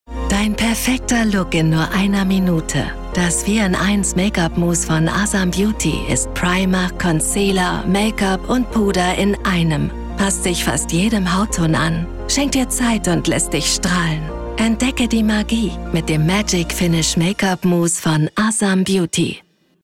Natural, Cool, Versátil, Cálida
Comercial
The sound of her voice is middle-aged (about 30-50 years), warm, dynamic and changeable.